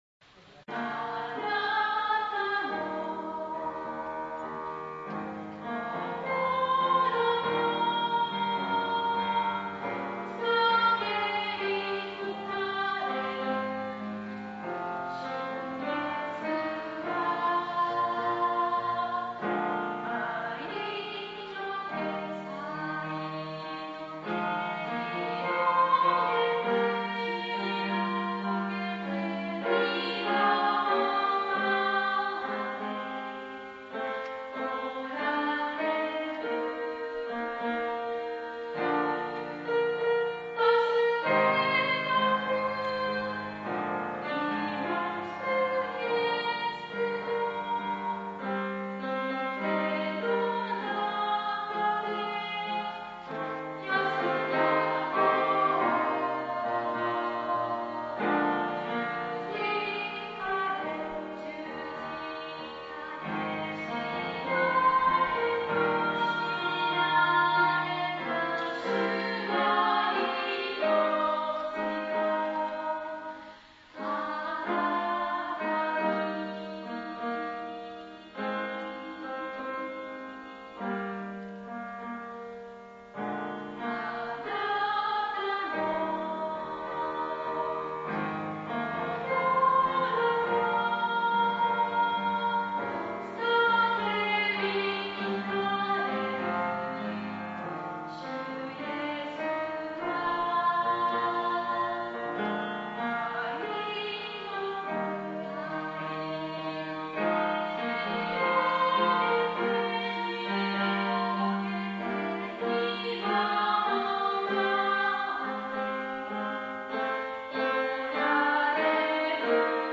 唄